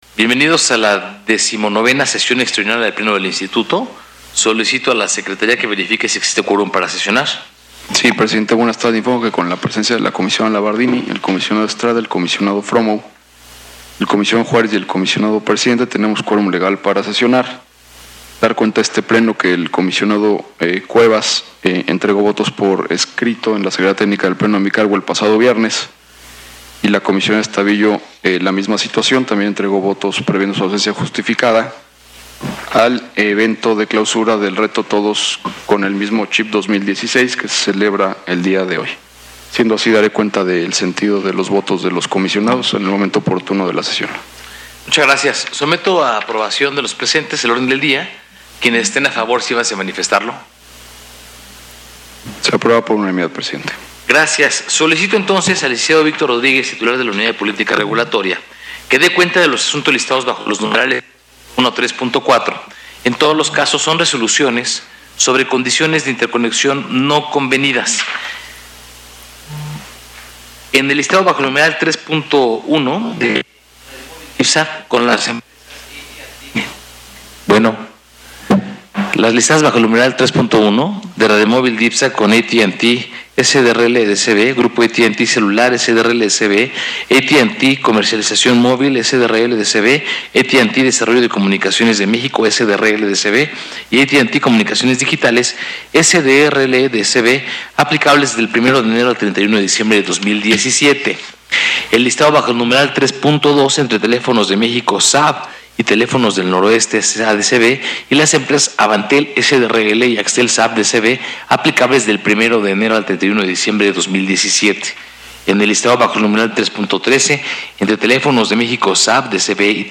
XIX Extraordinaria del Pleno 22 de noviembre de 2016